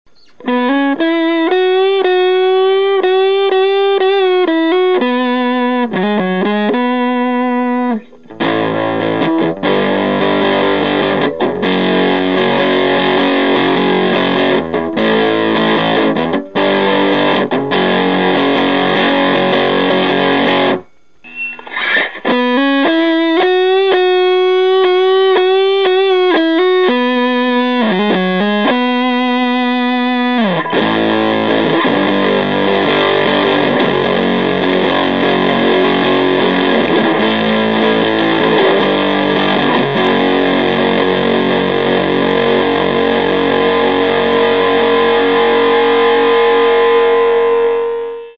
Tonebender Professional MK II clips
The first bit (some single notes, then chords) is with Fuzz set at 50%, the second bit has Fuzz set at 100%.
How the clips were recorded:  Pretty guitar - effect - LM386 amp - 2x12 open cab